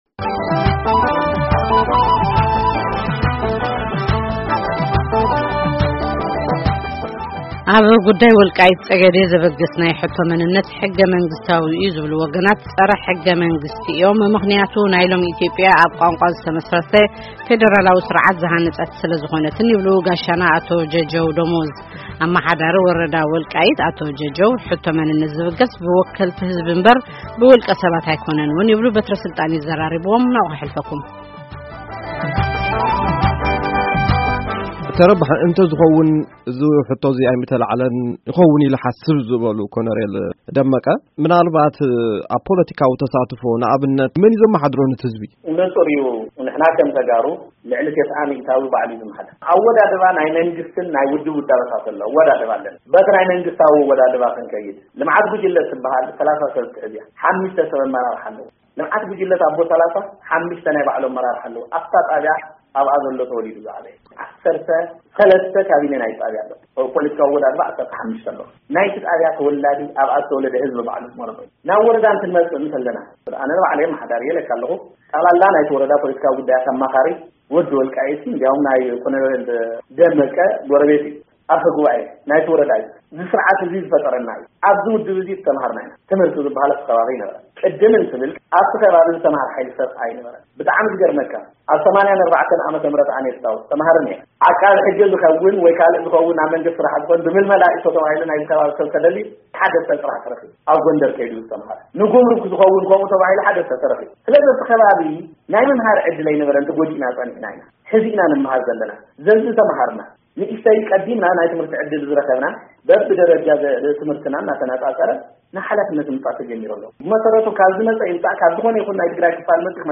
ቃለ መጠይቅ